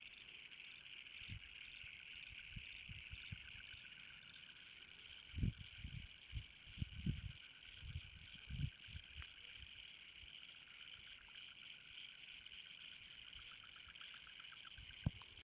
Frogs
At those times the calls of thousands of individuals, of several different species all merge to become a continuous wall of sound. Click here to hear how a swamp full of frogs sounds:
Westmere-frogs-2019.mp3